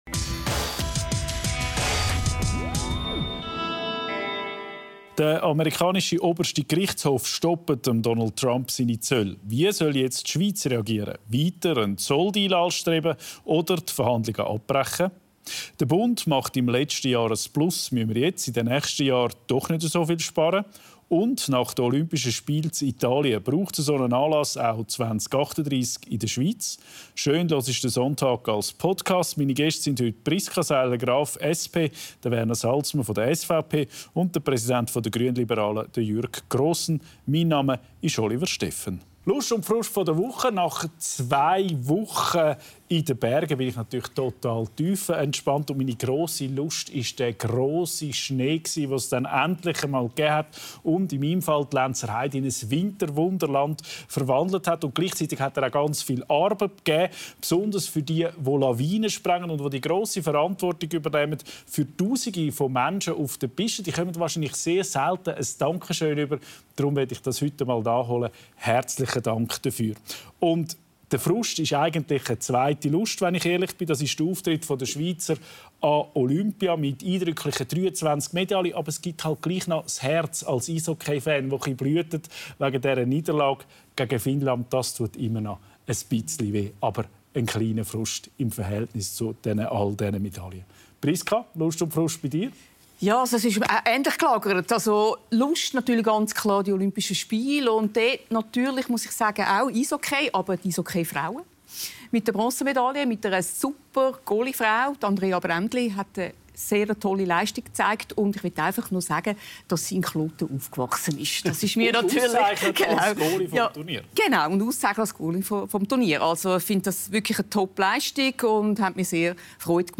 Olympia: Braucht die Schweiz solche Spiele im eigenen Land? Gäste: Jürg Grossen, GLP-Nationalrat Kanton Bern Werner Salzmann , SVP-Ständerat Kanton Bern Priska Seiler Graf, SP-Nationalrätin Kanton Zürich Moderation